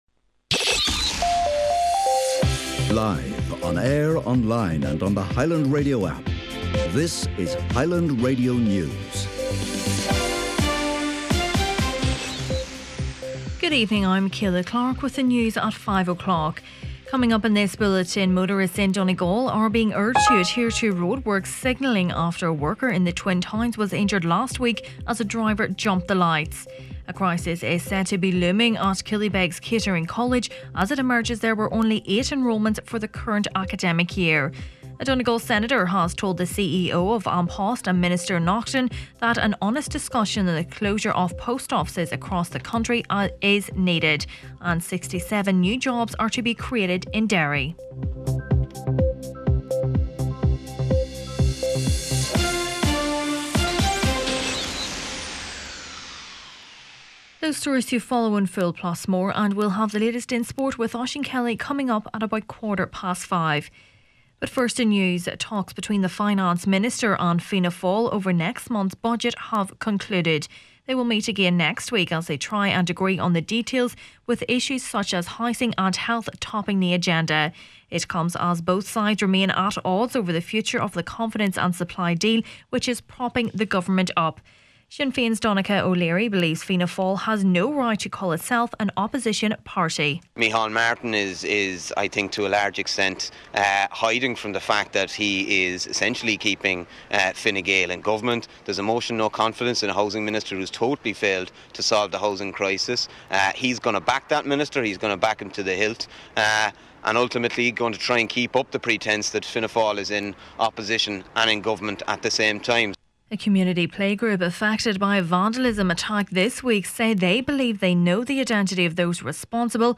Main Evening News, Sport and Obituaries Wednesday September 5th